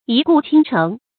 一顾倾城 yī gù qīng chéng
一顾倾城发音
成语注音 ㄧ ㄍㄨˋ ㄑㄧㄥ ㄔㄥˊ